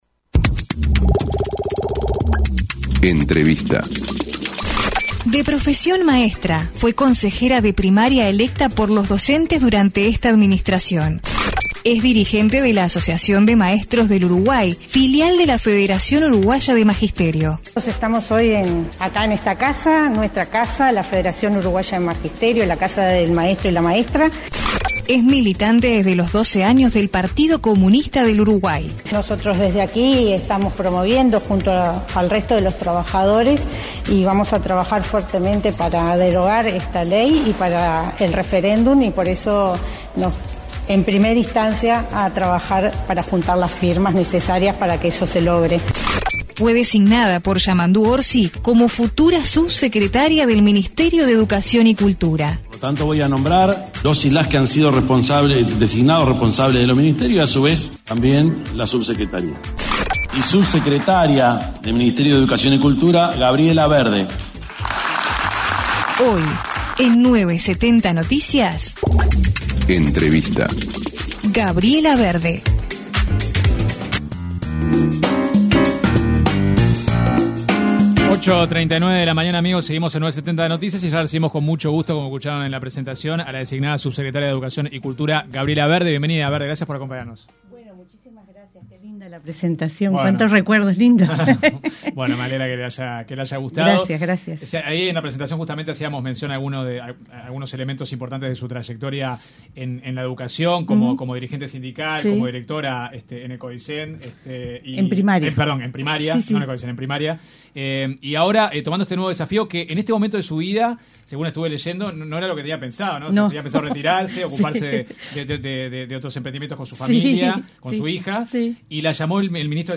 Escuche la entrevista completa aquí: La subsecretaria designada del Ministerio de Educación y Cultura para el próximo período de gobierno, Gabriela Verde, en diálogo con 970 Noticias, se refirió a los principales cambios, que a su entender, hay que realizar de la transformación educativa impulsada por Luis Lacalle Pou.